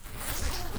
FABRIC_CLOTHING
ZIPPER_Short_3_mono.wav